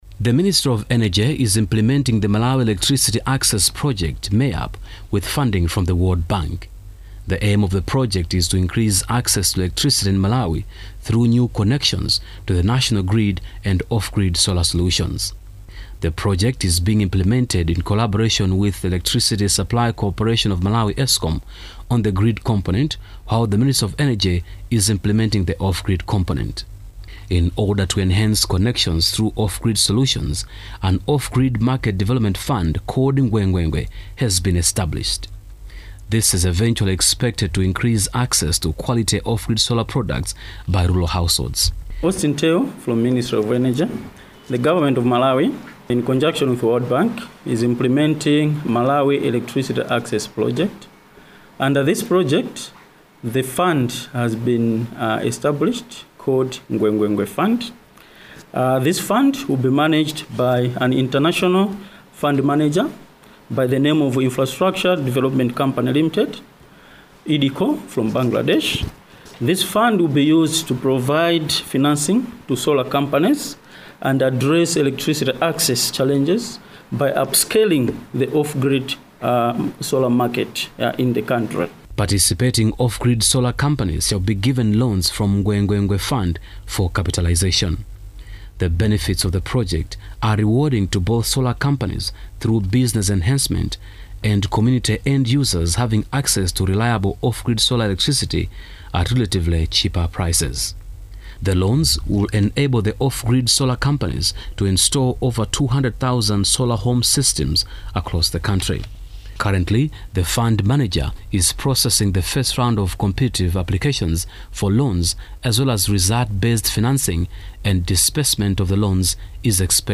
NNNF Launch Advert - English